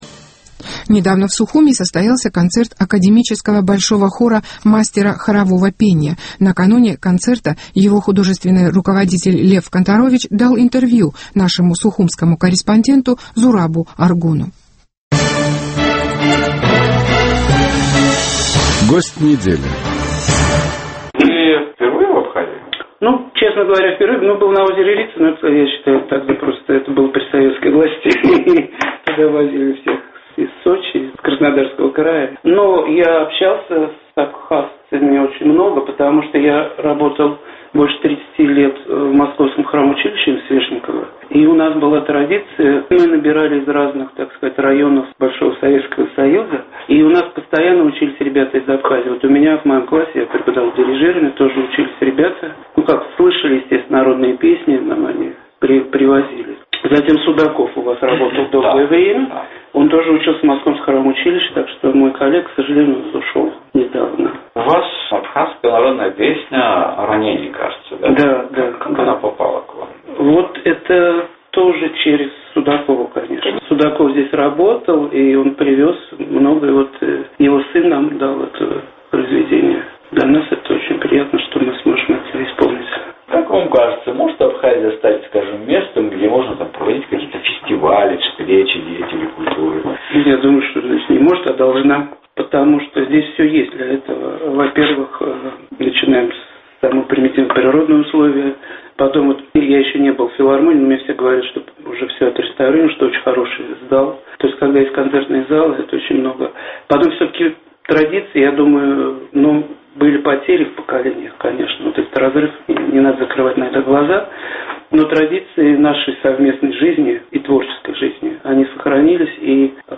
Российский хор выступил в Абхазии